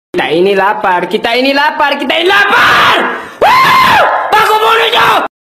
Suara meme Kita Ini Lapar
Kategori: Suara viral
Keterangan: Efek suara meme Kita ini lapar, kita ini lapar, kita ini lapar!